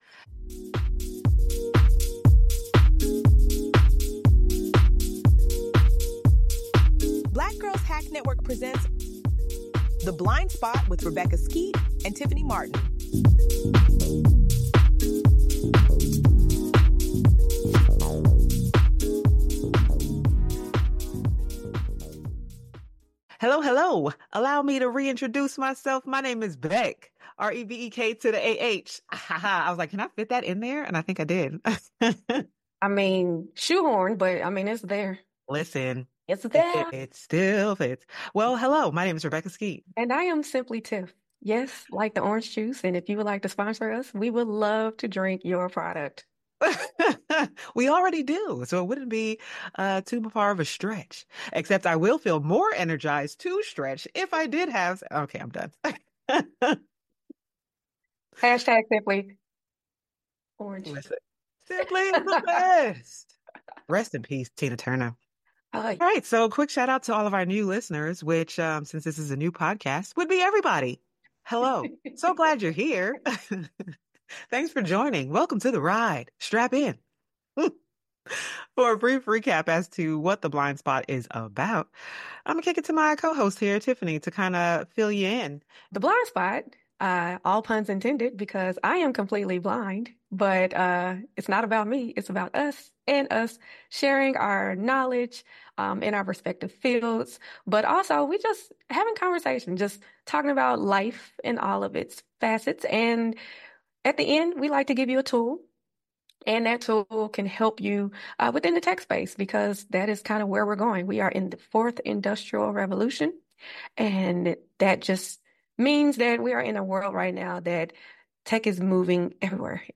They share personal stories of overcoming challenges, the importance of autonomy, and the role of advocacy in driving social change. The conversation emphasizes the need for individuals to take responsibility for their actions and decisions while navigating the complexities of identity and independence.